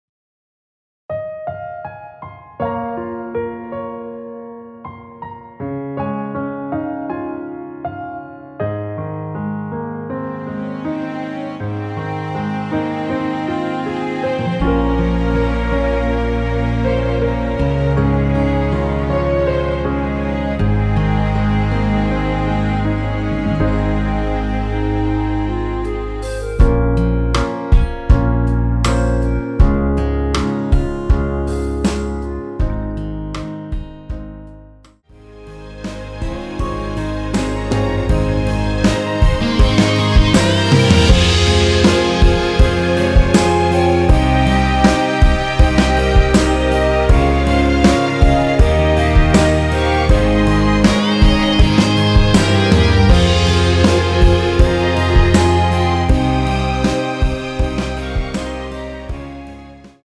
원키에서(+2)올린 멜로디 포함된 MR입니다.
Ab
앞부분30초, 뒷부분30초씩 편집해서 올려 드리고 있습니다.
(멜로디 MR)은 가이드 멜로디가 포함된 MR 입니다.